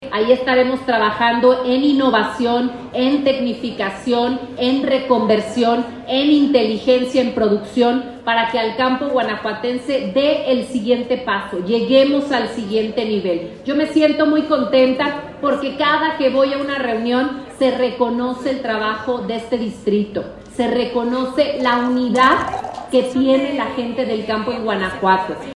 AudioBoletines
Irapuato, Gto. 15 de mayo del 2025 .- Autoridades municipales y estatales se reunieron en las instalaciones del Distrito de Riego 011 para refrendar su compromiso con el cuidado del agua.
Libia Denisse García, gobernadora